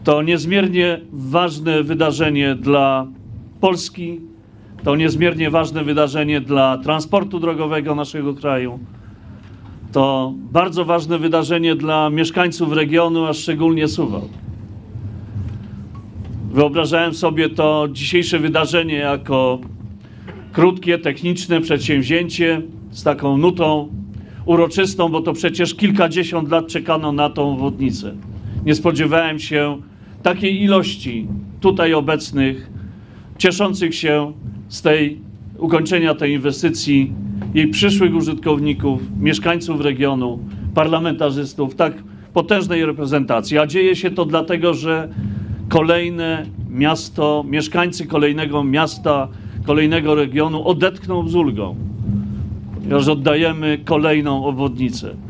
Uroczystego otwarcia 13-kilometrowej długości, nowej drogi ekspresowej, dokonali w sobotę (13.04.19) przed południem, zebrani na zachodnim węźle trasy przedstawiciele rządu, samorządu i drogowcy.
Minister infrastruktury podkreślał rangę wydarzenia w skali miasta, ale też kraju.